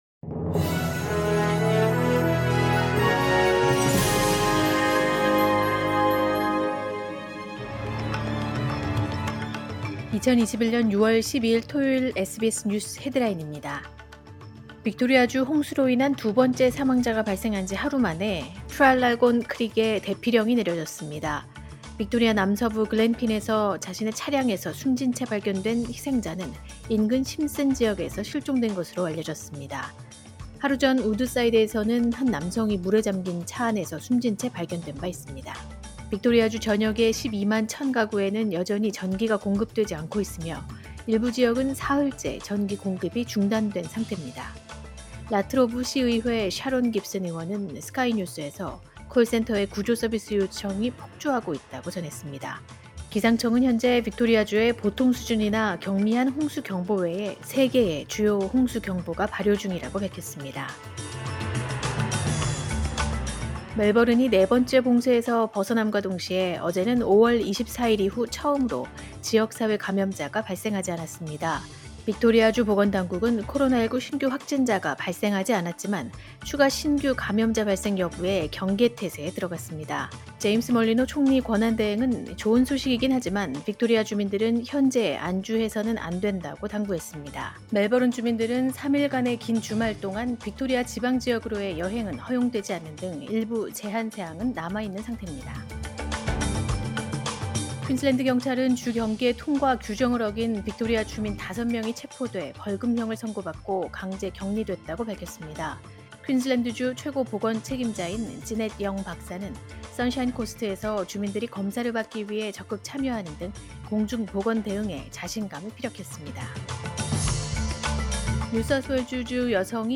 2021년 6월 12일 토요일 SBS 뉴스 헤드라인입니다.